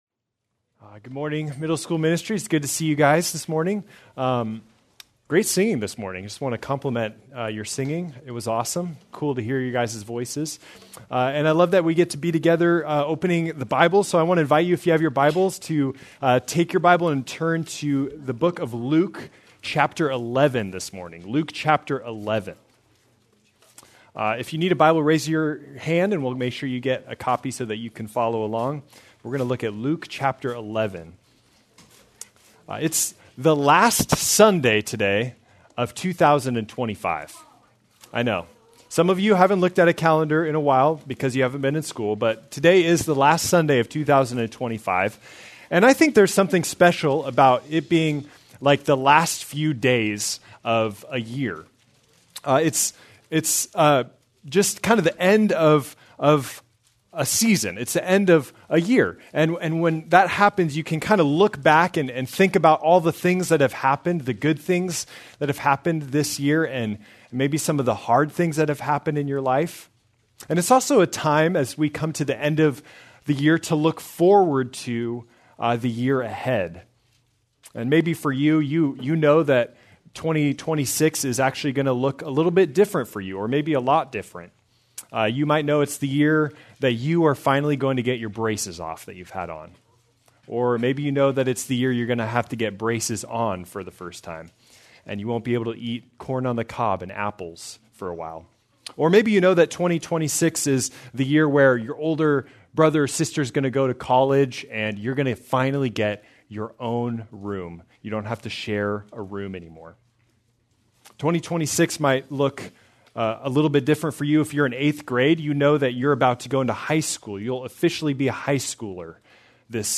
December 28, 2025 - Sermon | Xchange | Grace Community Church